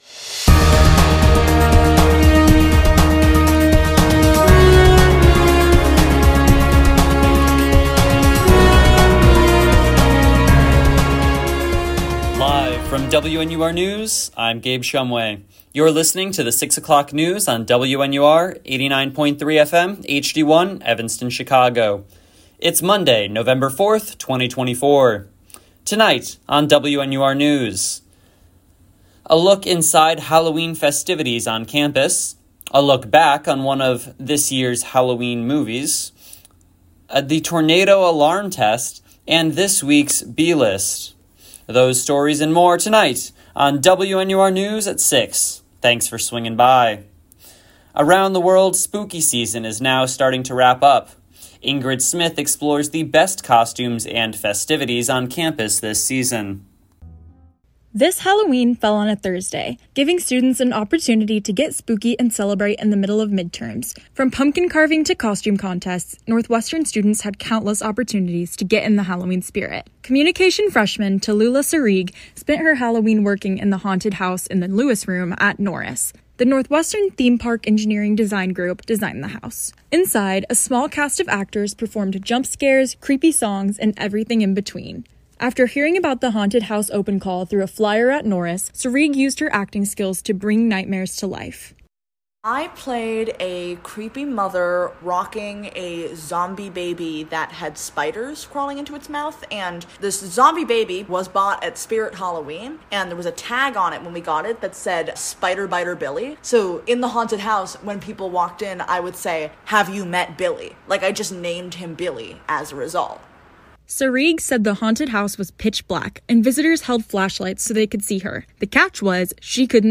WNUR News broadcasts live at 6 pm CST on Mondays, Wednesdays, and Fridays on WNUR 89.3 FM.